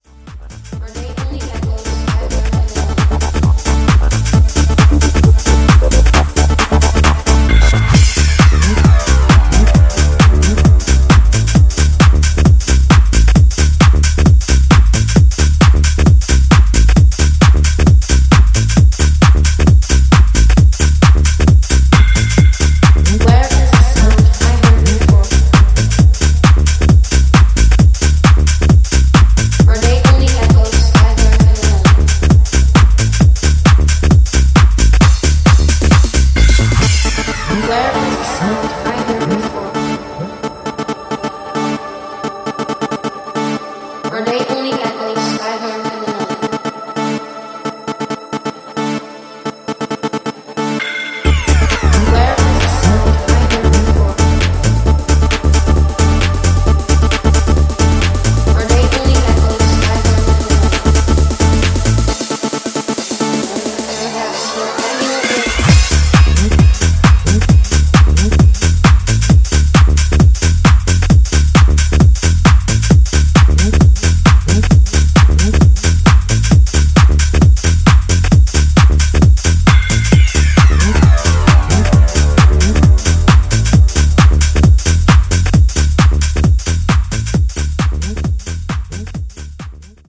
90sプログレッシヴ・ハウス/トランスの影響を昇華した極彩色の楽曲群を展開